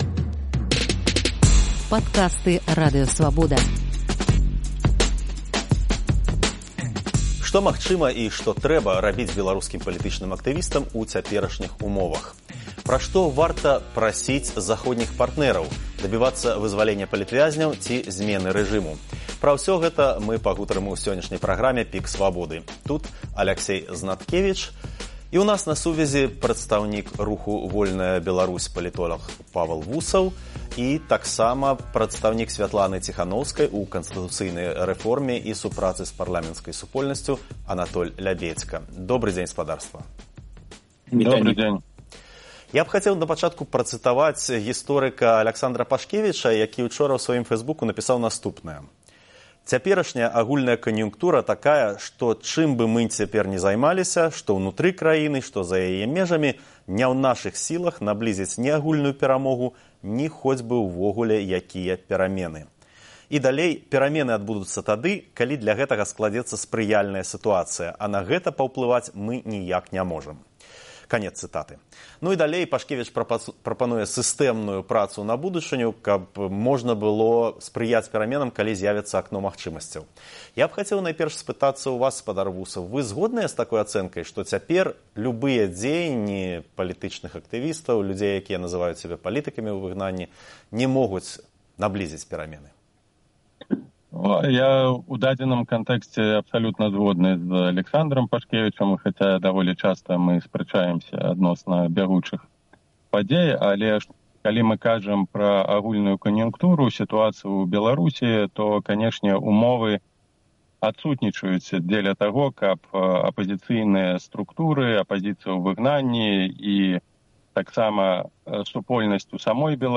Што магчыма і што трэба рабіць беларускім палітычным актывістам у цяперашніх умовах? Пра што варта прасіць заходніх партнэраў – дабівацца вызваленьня палітвязьняў ці зьмены рэжыму? Пра гэта ў жывым эфіры Свабода Premium дыскутуюць прадстаўнік руху «Вольная Беларусь»